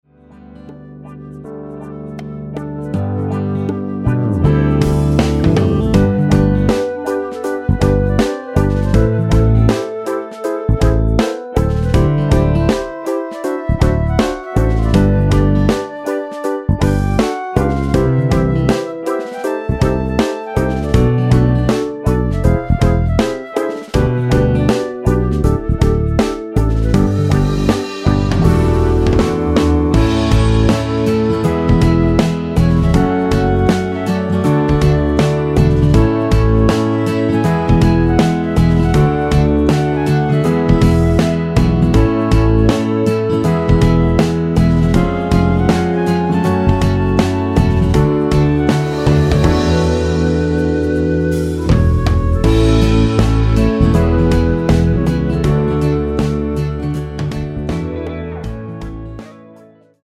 앞부분30초, 뒷부분30초씩 편집해서 올려 드리고 있습니다.
곡명 옆 (-1)은 반음 내림, (+1)은 반음 올림 입니다.
(멜로디 MR)은 가이드 멜로디가 포함된 MR 입니다.